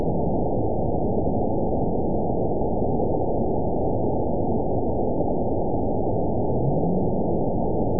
event 920518 date 03/28/24 time 16:50:13 GMT (1 year, 1 month ago) score 9.39 location TSS-AB03 detected by nrw target species NRW annotations +NRW Spectrogram: Frequency (kHz) vs. Time (s) audio not available .wav